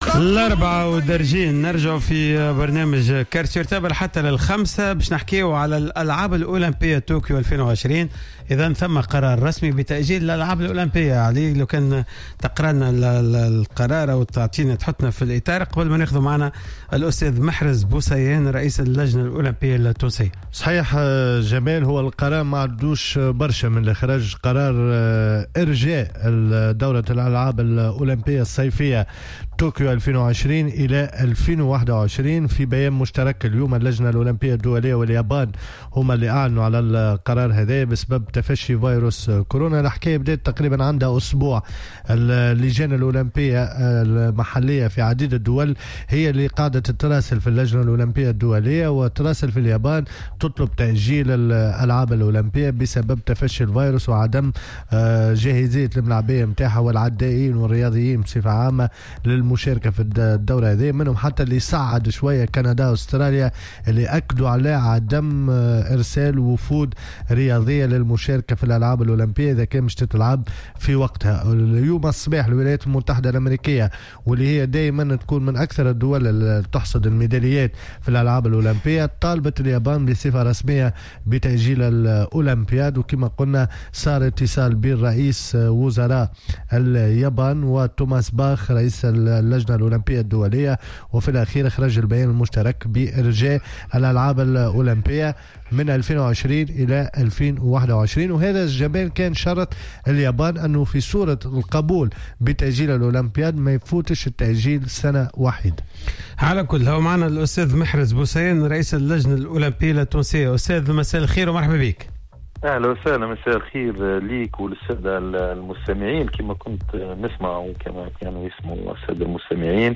و إلتزاما بقواعد الحجر الصحي العام ، تدخل كل فريق البرنامج عبر الهاتف